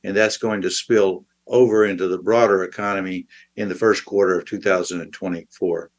during a video briefing on Friday